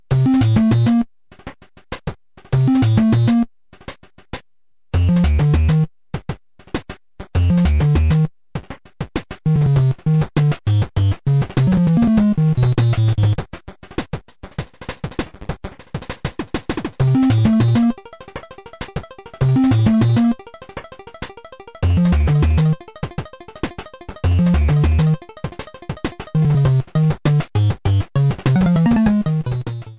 Started partway through the track and fadeout